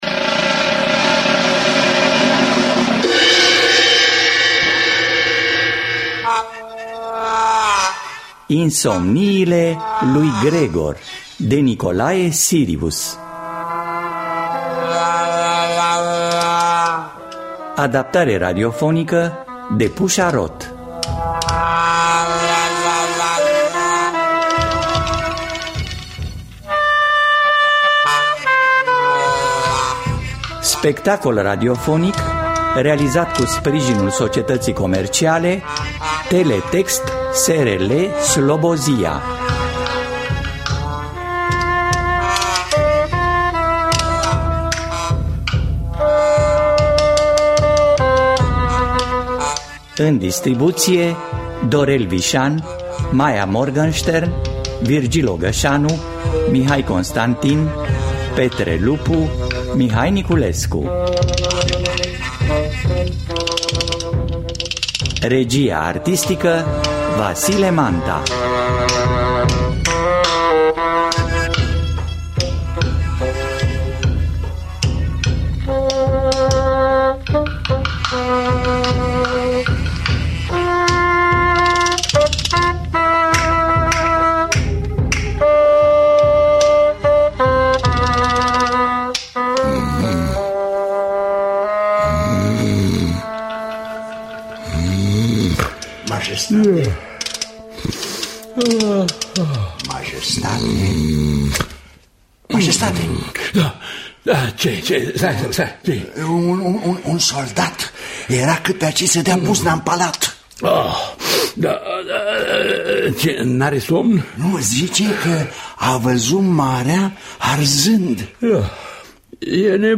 Adaptare radiofonică de Pușa Roth.